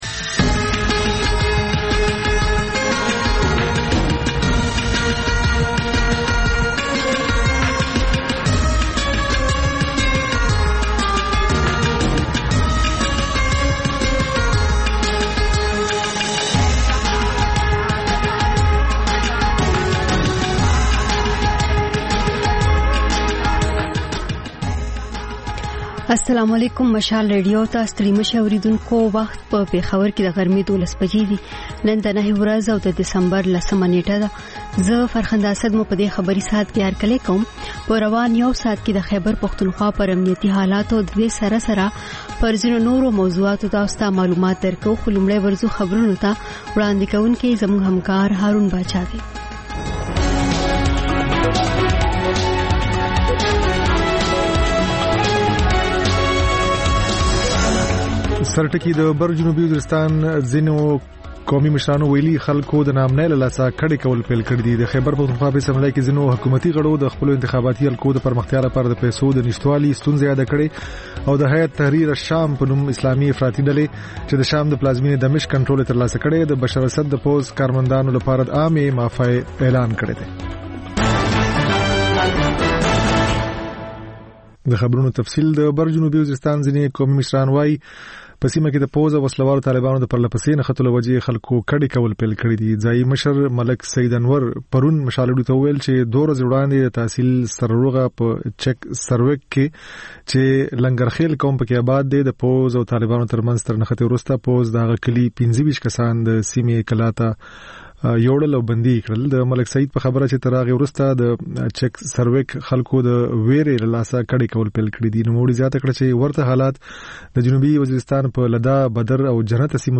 د مشال راډیو د ۱۴ ساعته خپرونو په لومړۍ خبري ګړۍ کې تازه خبرونه، رپورټونه، شننې، مرکې او کلتوري، فرهنګي رپورټونه خپرېږي.